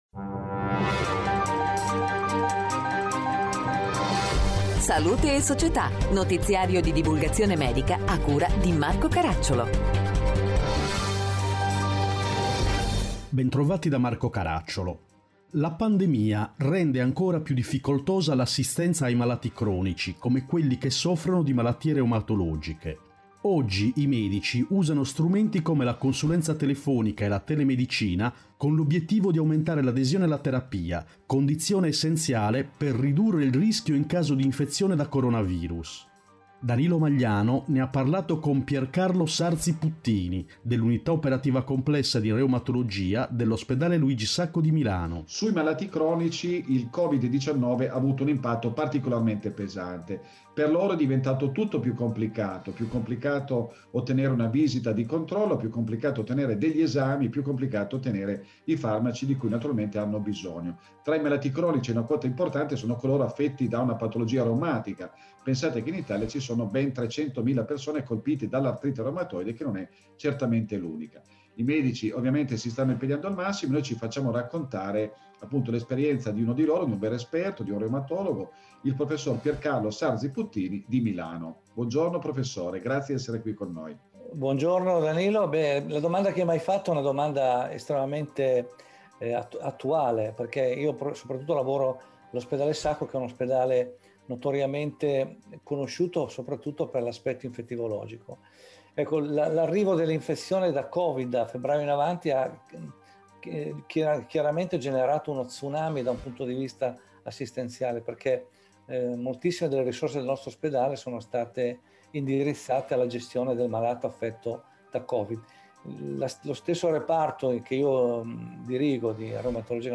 Salute/Società: rubrica di divulgazione medico-scientifica prodotta da Emmecom
Puntata con sigla